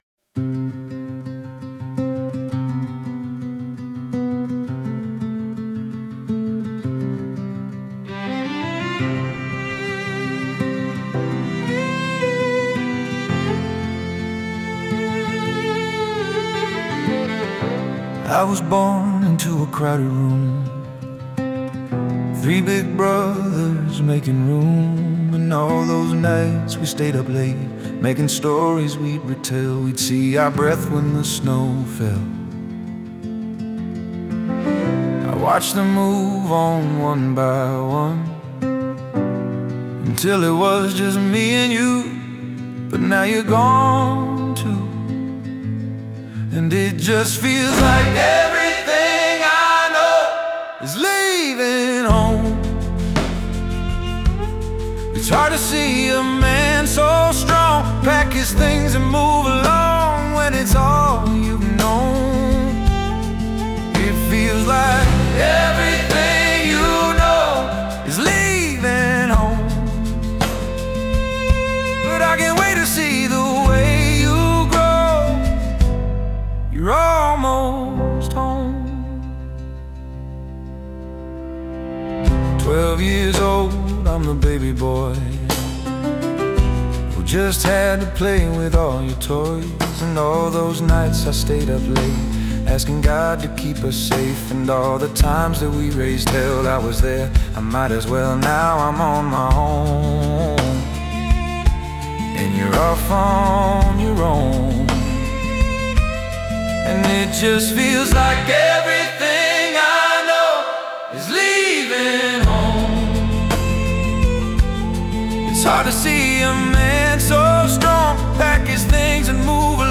Discover the Heartbeat of Acoustic Storytelling